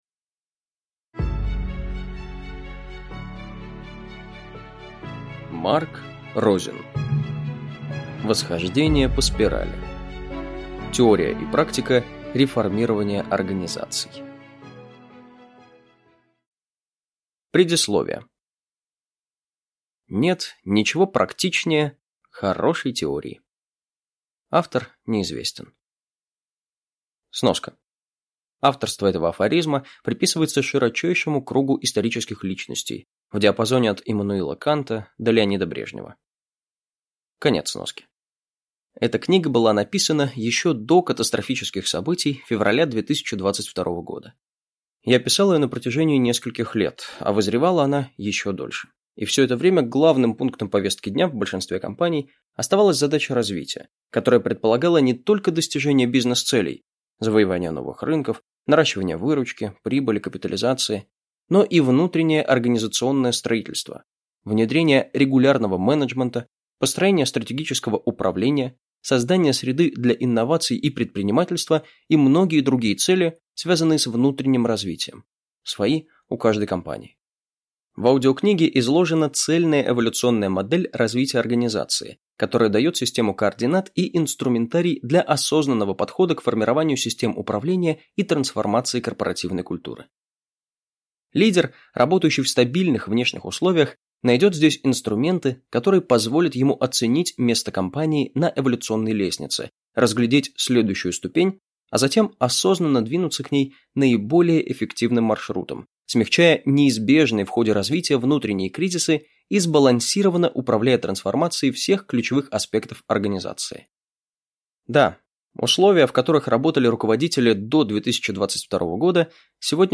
Аудиокнига Восхождение по спирали. Теория и практика реформирования организаций | Библиотека аудиокниг